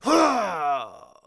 fall_2.wav